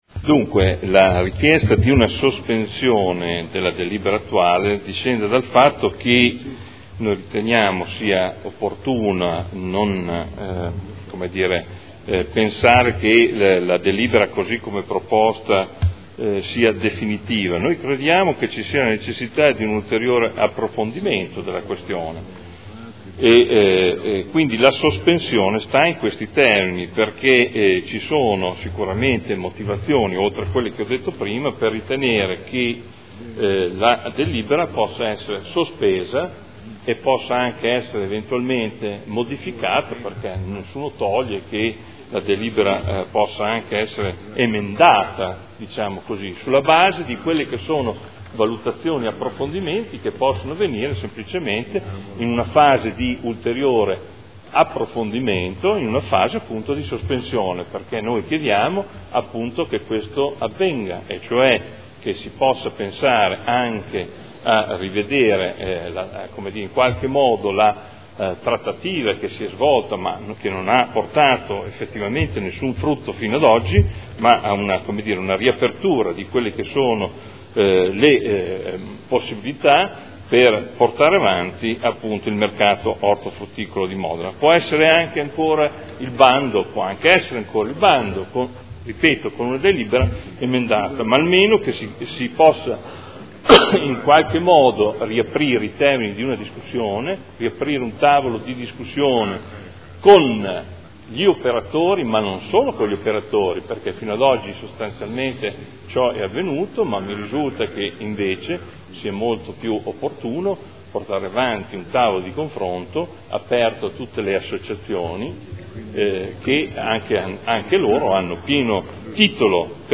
Adolfo Morandi — Sito Audio Consiglio Comunale
Seduta del 18/06/2012. Dibattito su sospensiva alla proposta di deliberazione. Nuovo Mercato Ortofrutticolo all'Ingrosso